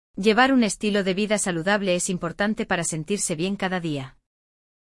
Hoje, vamos falar sobre hábitos de vida saudável e como expressá-los em espanhol. Através de um diálogo envolvente entre duas amigas, você aprenderá palavras e expressões essenciais para falar sobre alimentação equilibrada, exercícios físicos e bem-estar.
Não! O podcast é estruturado para que qualquer pessoa possa entender, com explicações didáticas e pausas para repetição.